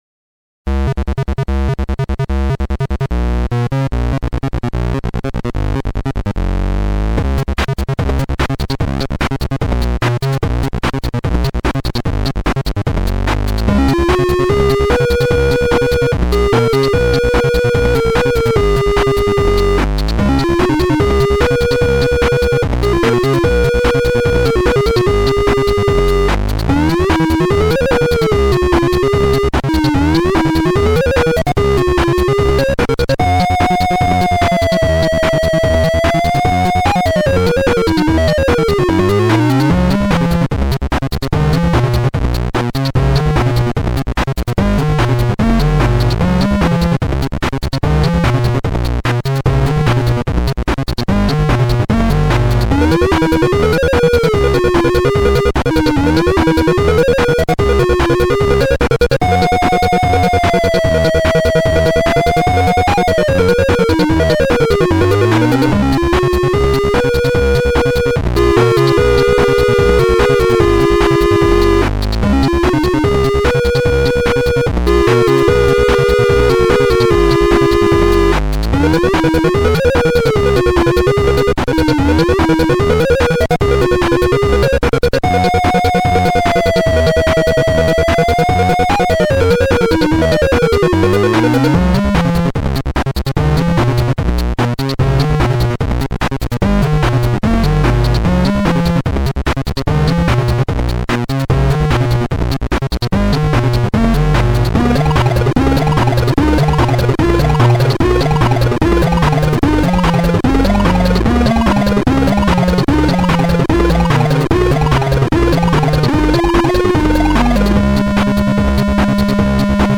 (sampled from PAL hw)
Datapop is a music demo for the unexpanded Commodore Vic-20 computer.
All you hear is the original VIC oscillators. No volume register modulations.
(sampled from my PAL Vic-20)